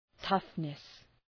Προφορά
{‘tʌfnıs}